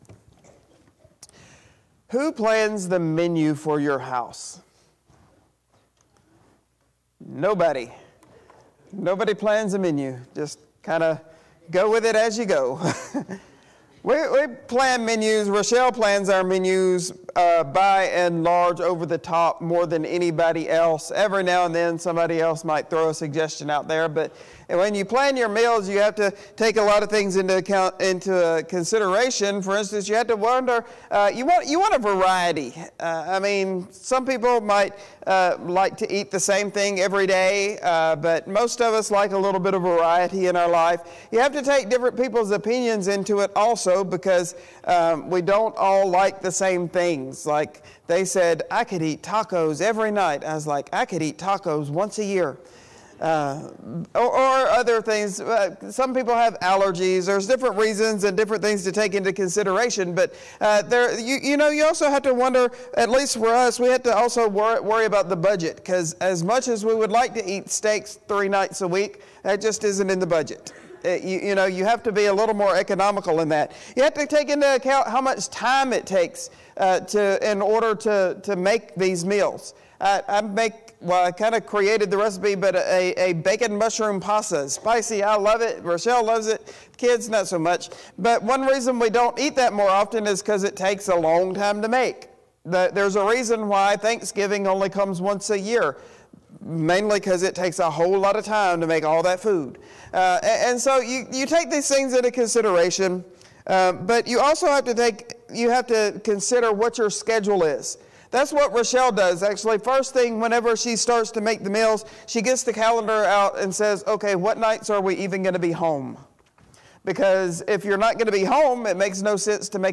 Sermons | Eastwood Baptist Church